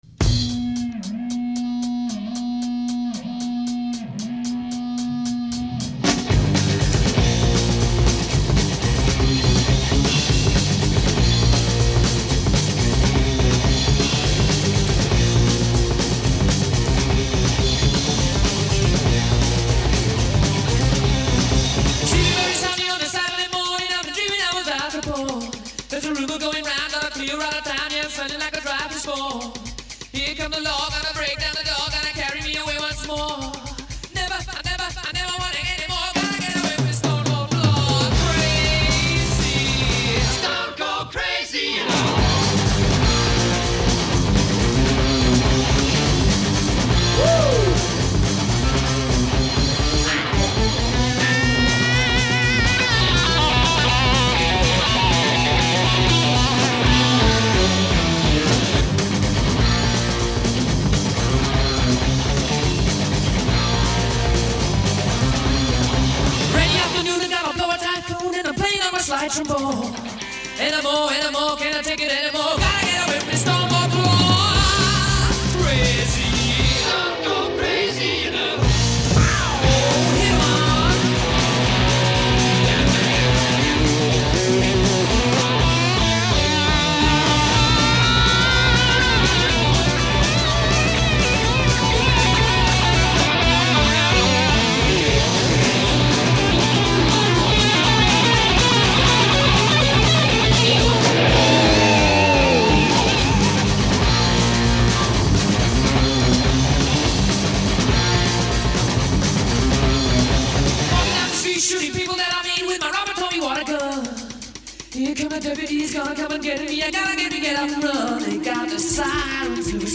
Очень камерное приятное исполнение.